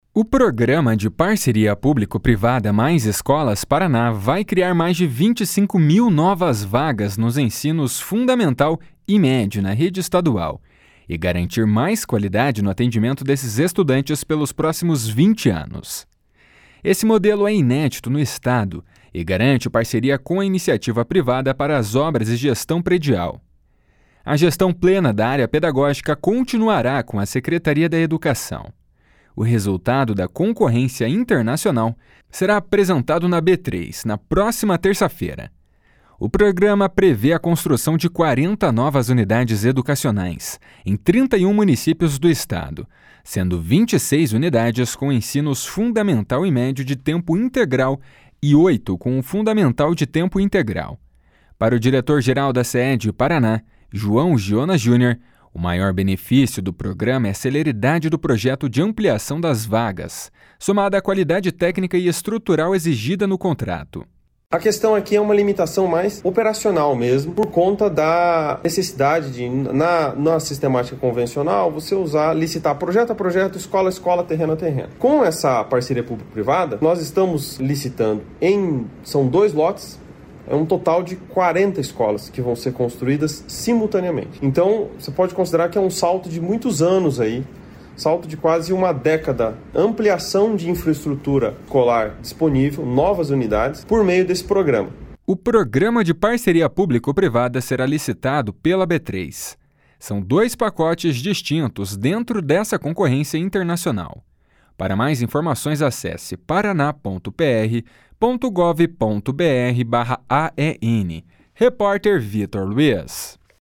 O programa prevê a construção de 40 novas unidades educacionais em 31 municípios do Estado, sendo 26 unidades com ensinos Fundamental e Médio de tempo integral e oito com o Fundamental de tempo integral. Para o diretor-geral da Seed-PR, João Giona Júnior, o maior benefício do programa é a celeridade do projeto de ampliação das vagas, somada à qualidade técnica e estrutural exigida no contrato.